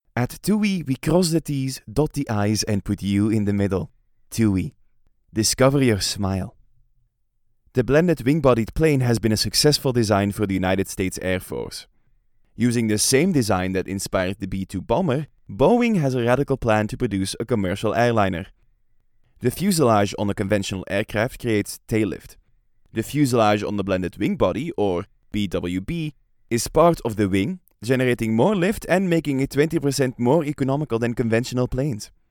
Flemish, Male, Home Studio, 20s-40s